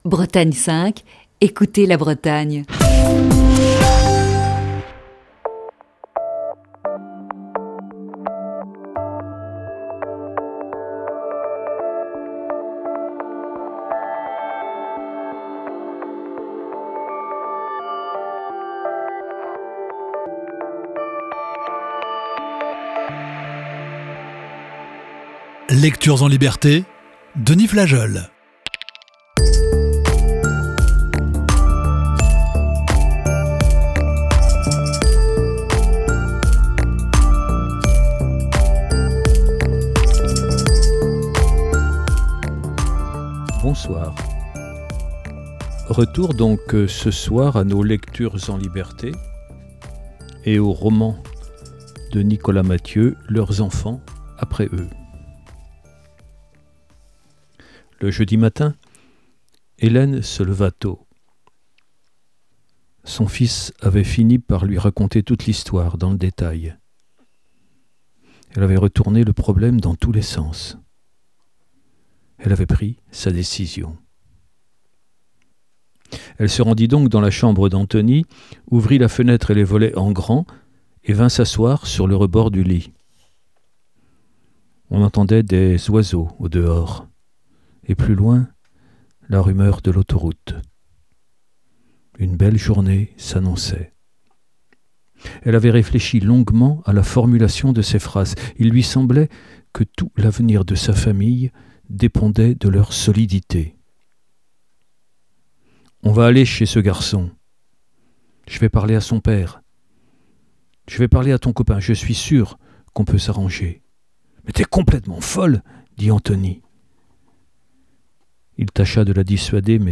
Émission du 24 avril 2020.